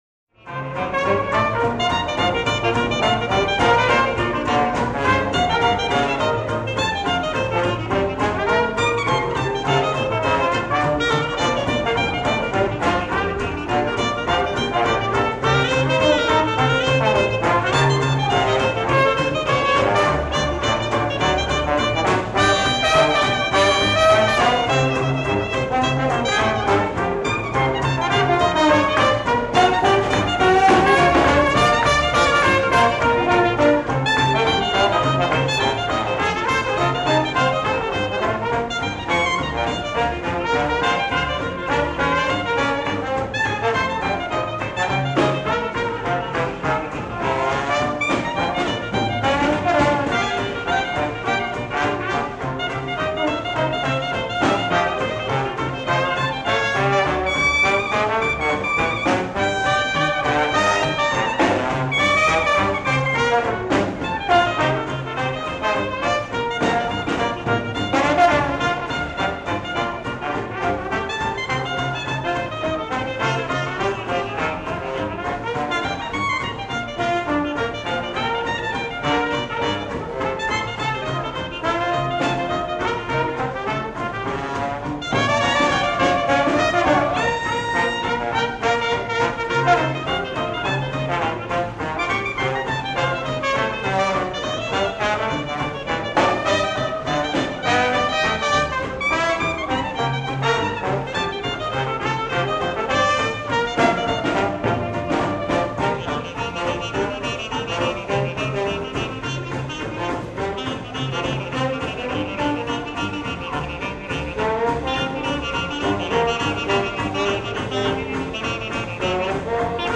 Recording fades.